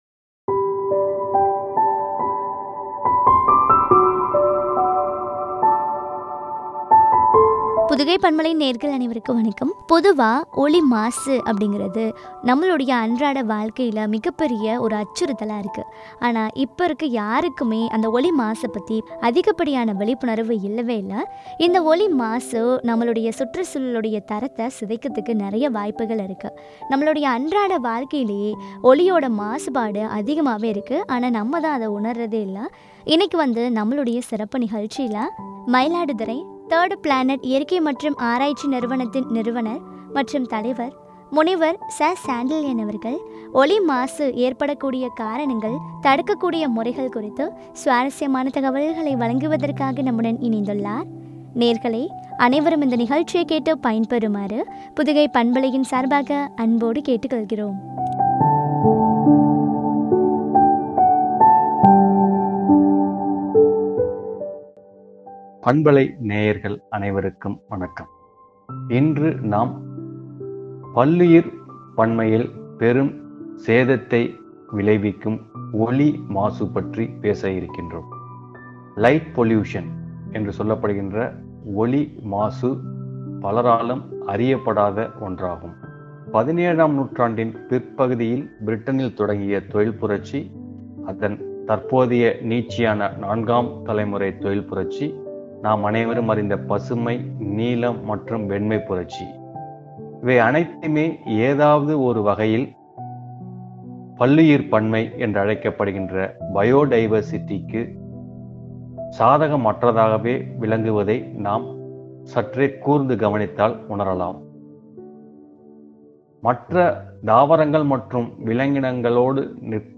தடுக்கும் முறைகளும் ” குறித்து வழங்கிய உரையாடல்.